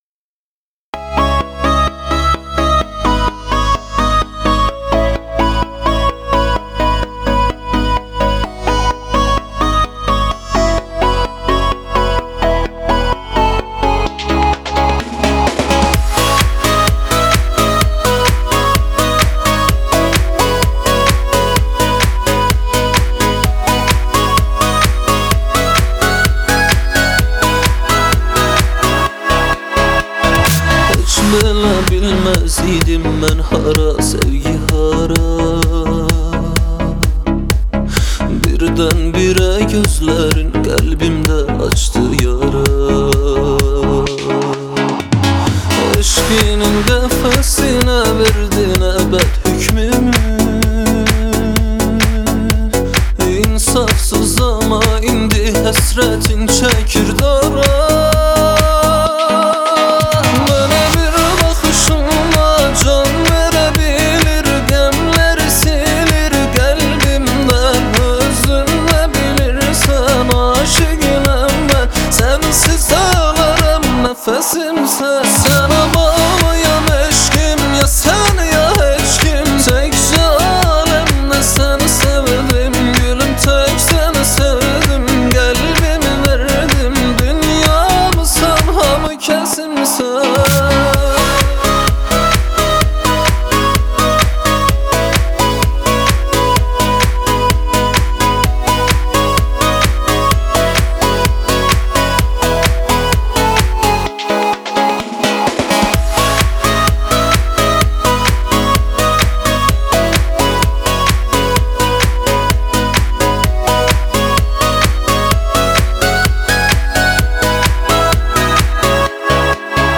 آهنگ آذربایجانی آهنگ شاد آذربایجانی آهنگ هیت آذربایجانی